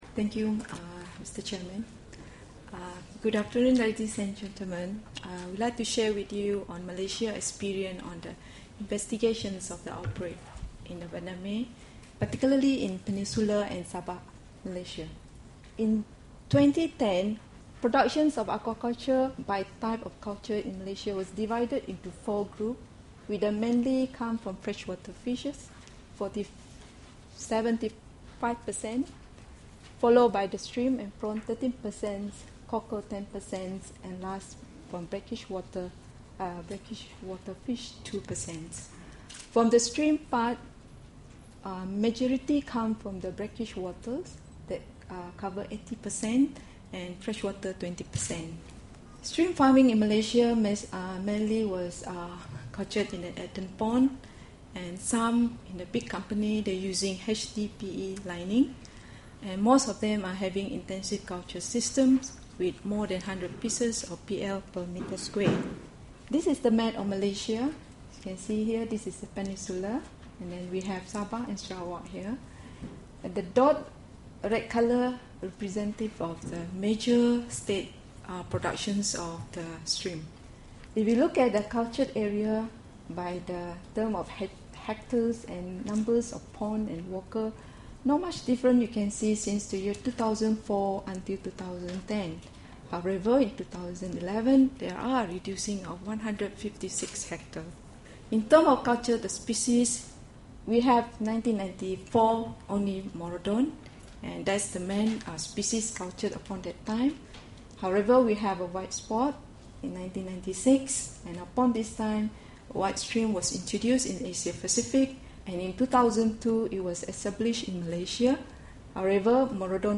Presentation on the impact of acute hepatopancreatic necrosis syndrome in Malaysia.